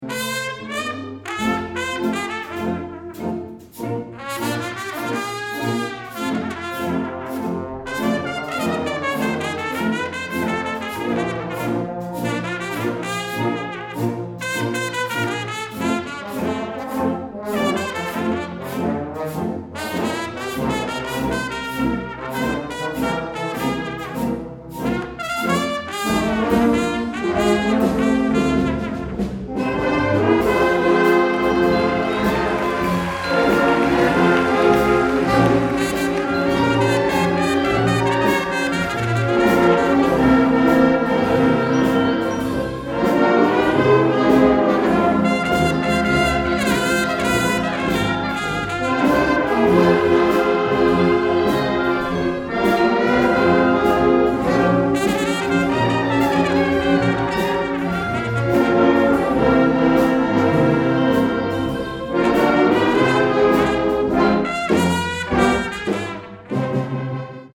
Below are music excerpts from some of our concerts.
2009 Winter Concert
December 20, 2009 - San Marcos High School